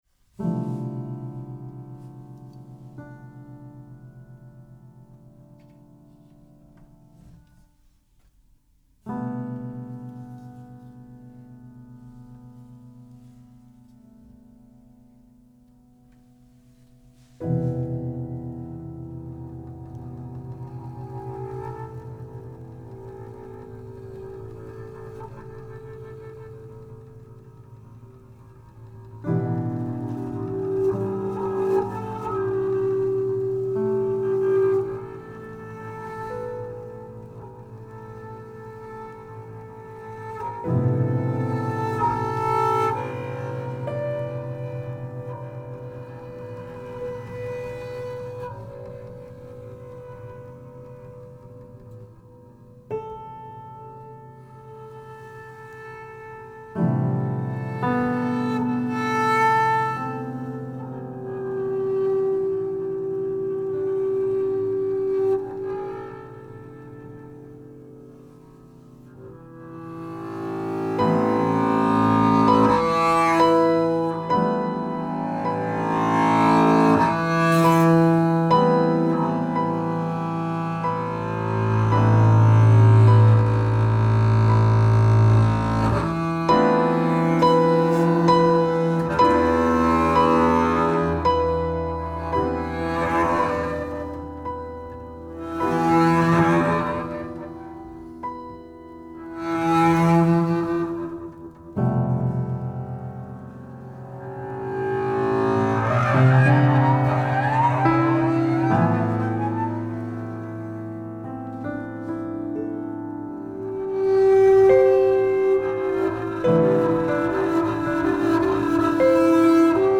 double bass
prepared and unprepared piano